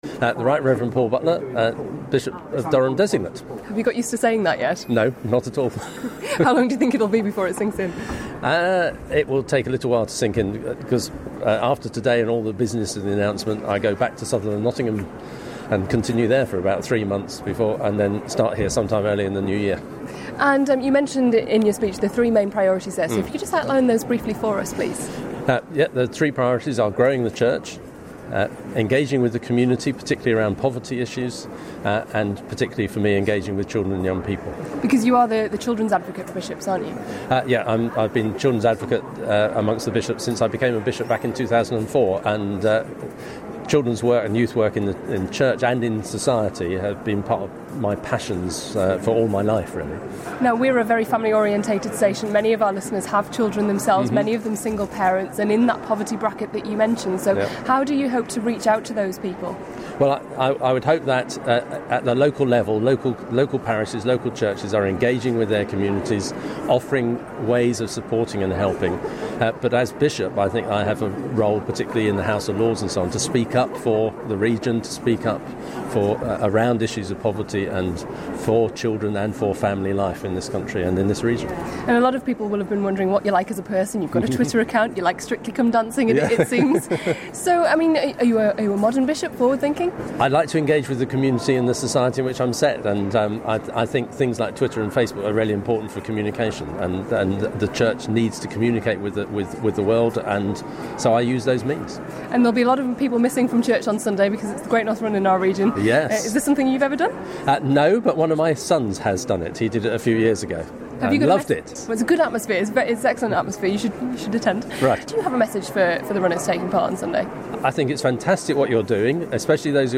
Bishop of Durham's first interview at Durham Cathedral